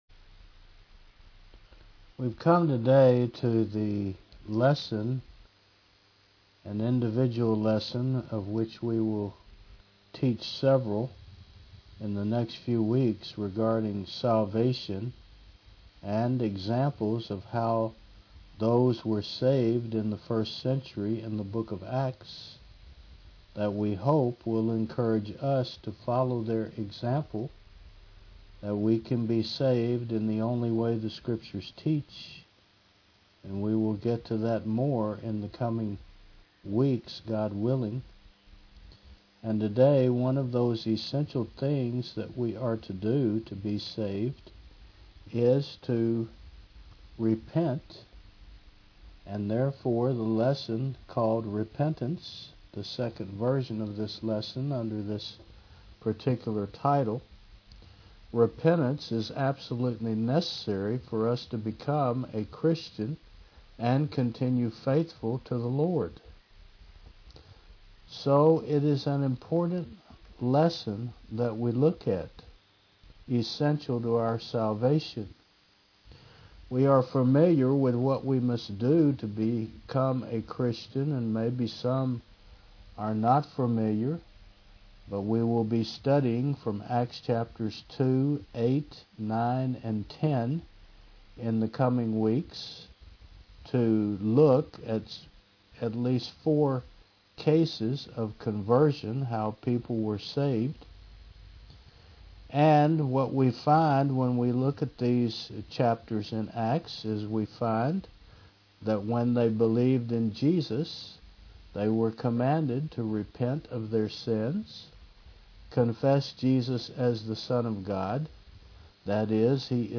2026 Repentance v2 Preacher